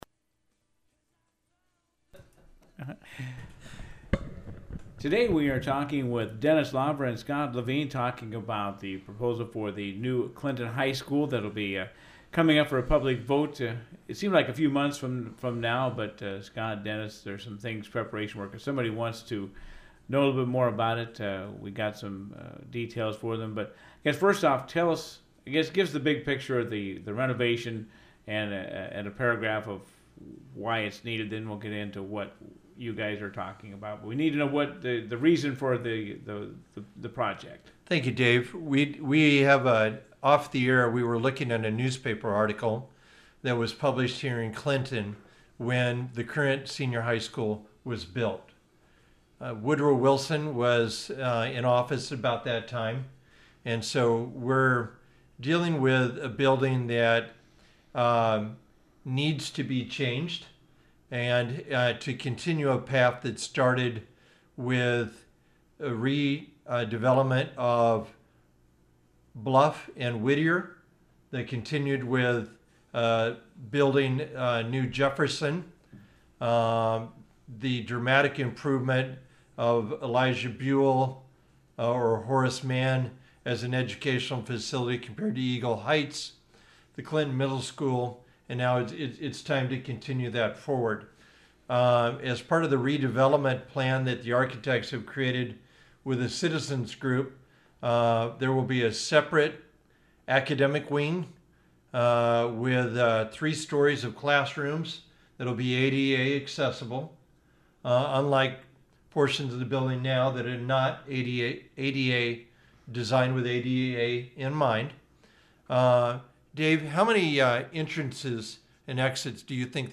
The Task Force wants to show the public some of the behind the scenes condition of the high school preceeding a bond issue vote on a renovation plan. Hear more about the tours in this conversation